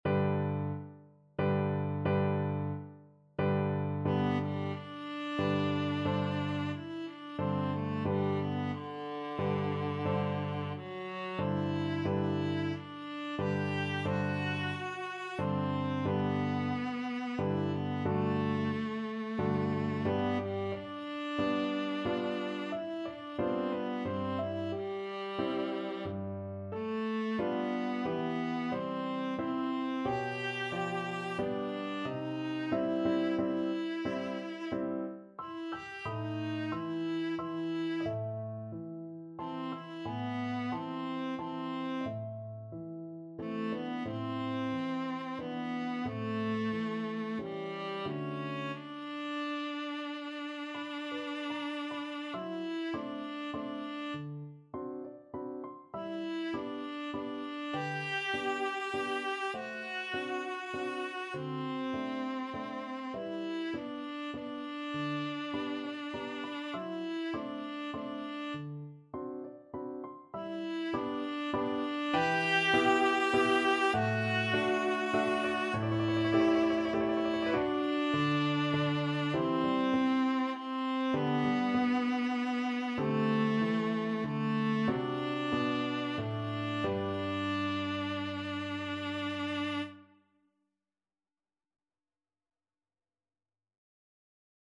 3/4 (View more 3/4 Music)
~ = 90 Allegretto moderato
Classical (View more Classical Viola Music)